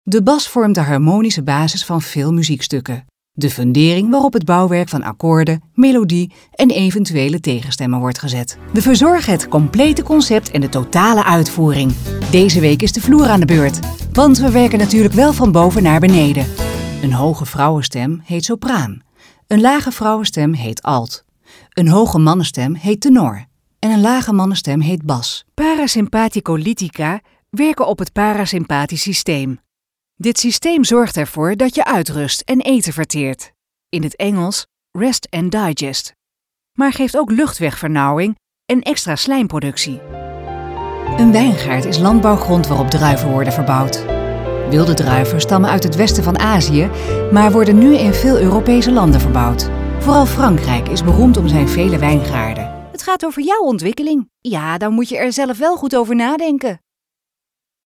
Corporate | e-learning
Haar communicatie stijl is nuchter, trefzeker, warm en betrokken; to-the-point.
Accentloos, helder en met de juiste toon voor jouw doelgroep.